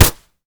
kick_soft_jab_impact_05.wav